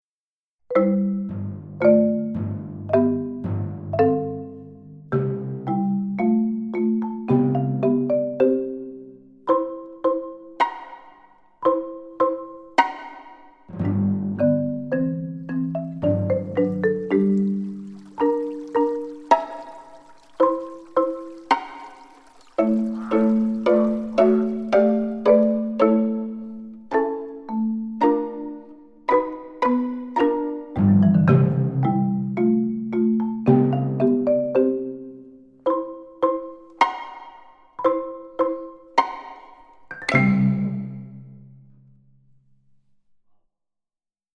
Mit zwei Takten Vorspiel